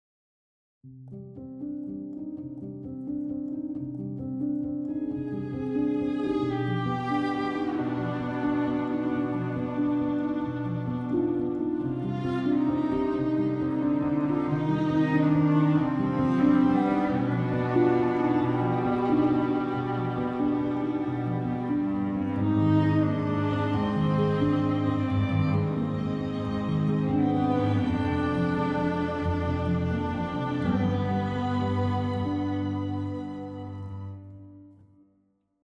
Пишу для короткометражки фоновую композицию, что скажете?